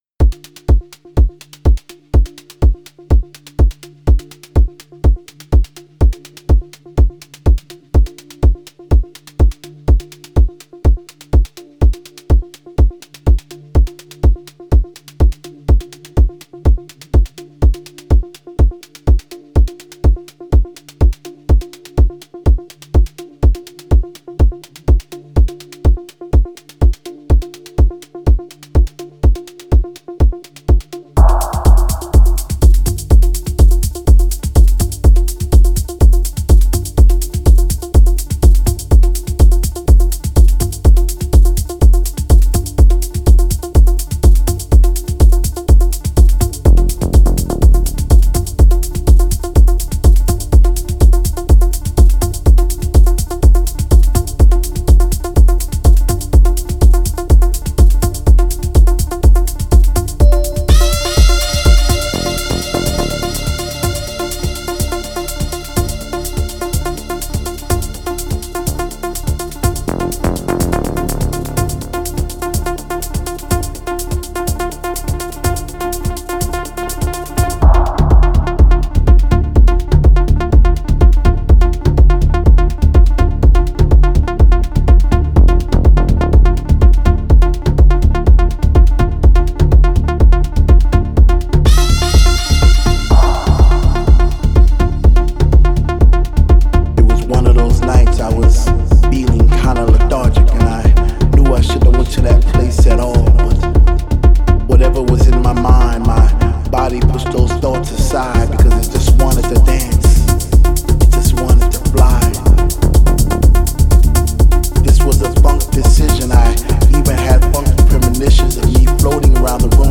• Жанр: Dance, Techno